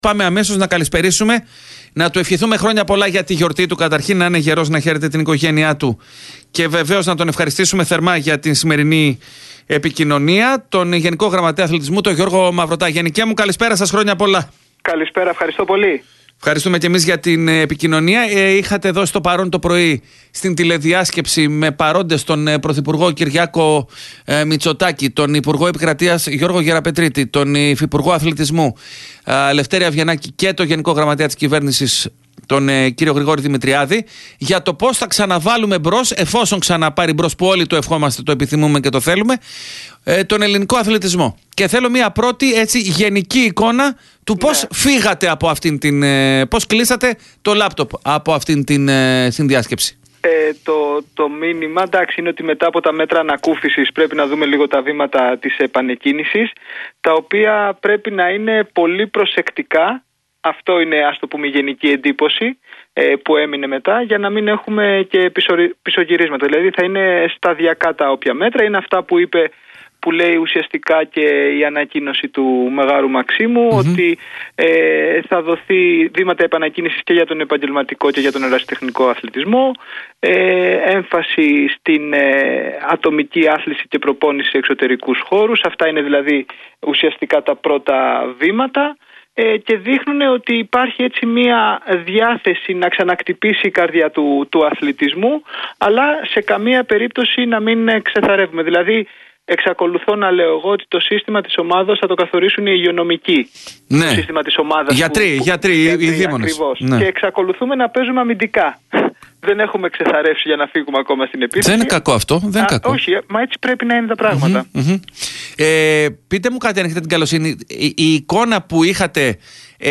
έδωσε στον Realfm 97,8 και στην εκπομπή «Real Sports»
Mayrvtas_ston_real_fm_id38613.mp3